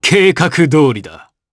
Roman-Vox_Skill2_jp.wav